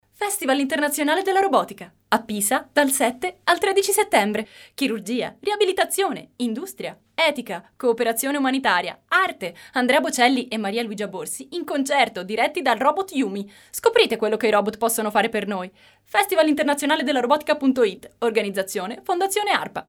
voce giovane, brillante, calda, versatile per spot, documentari, doppiaggi, audiolibri e quant'altro.
Sprechprobe: Werbung (Muttersprache):